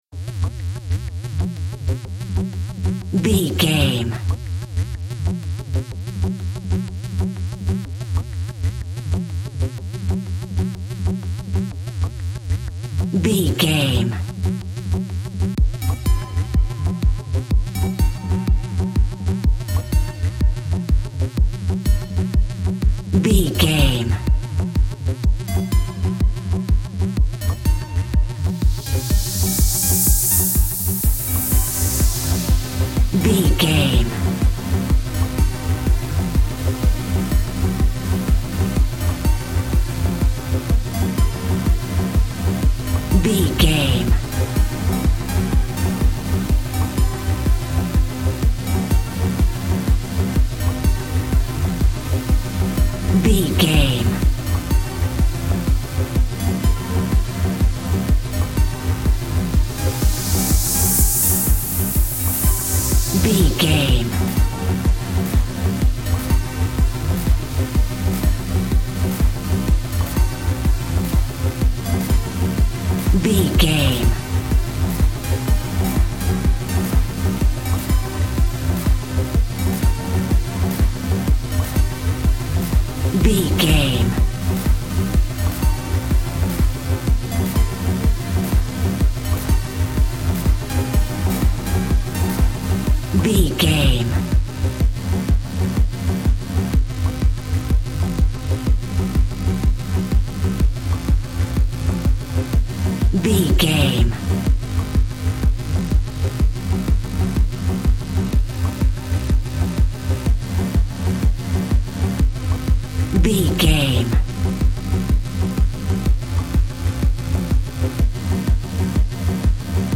Ionian/Major
E♭
dreamy
ethereal
joyful
synthesiser
drum machine
house
electro dance
synth leads
synth bass
upbeat